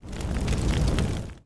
Index of /App/sound/effect/hit/blow_flame
fire_attack.wav